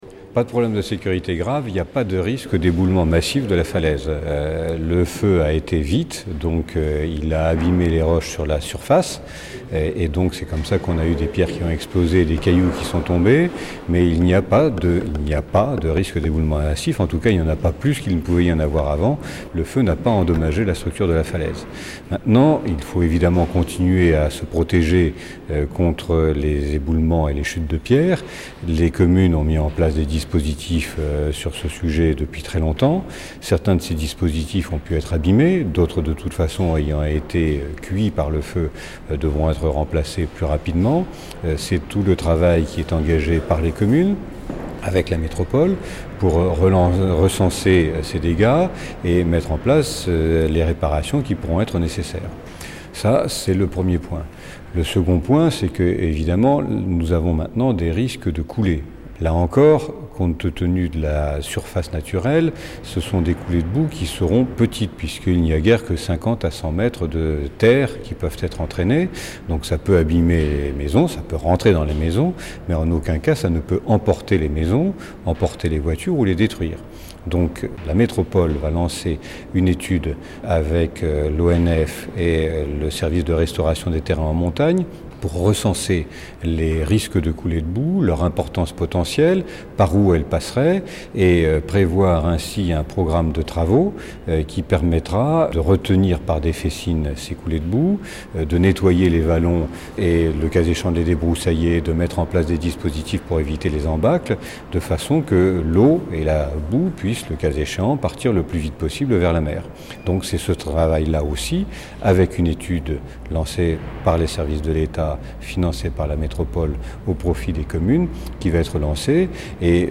Stéphane Bouillon, préfet de la région Provence-Alpes-Côte d’Azur, préfet des Bouches-du-Rhône a présidé une réunion sur les suites de l’incendie qui a touché les communes de Rognac, Vitrolles et Les Pennes-Mirabeau le 10 août dernier.